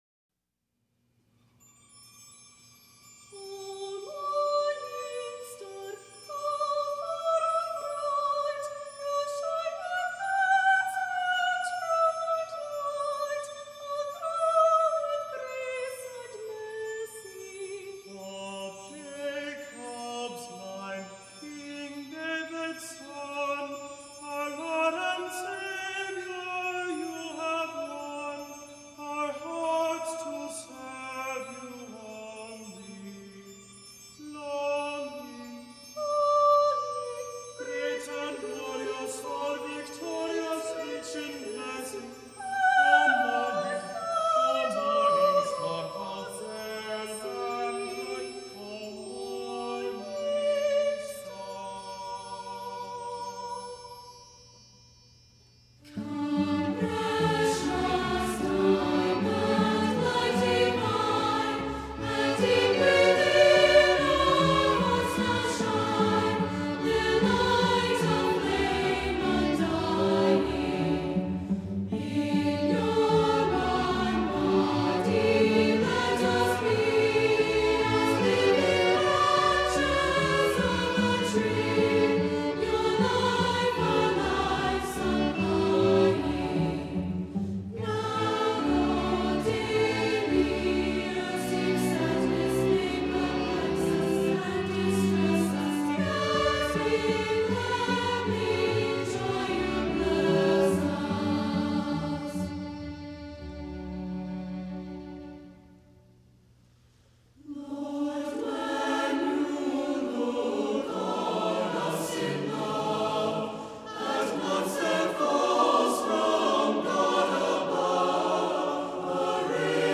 SATB divisi, Soprano & Tenor Solos, Oboe, String Quintet, Chimes, Assembly, and Organ